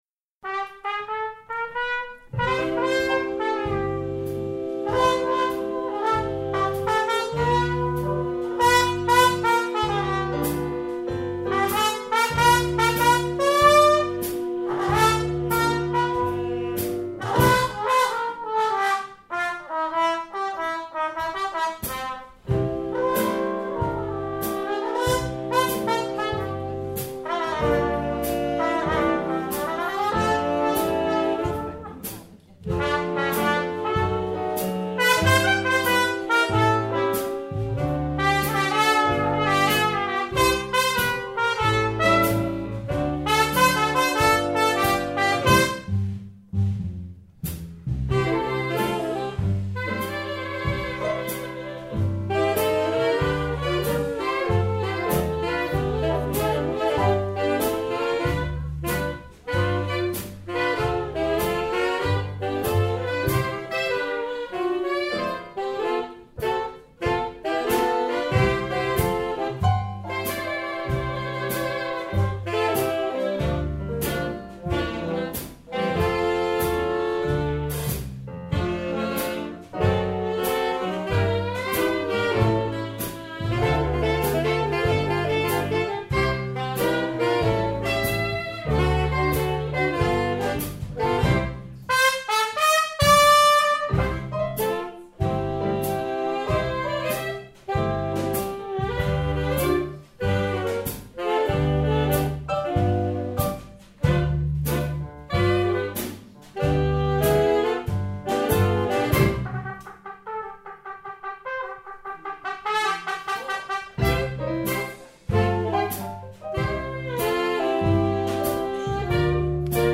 live bei verschiedenen Auftritten mitgeschnitten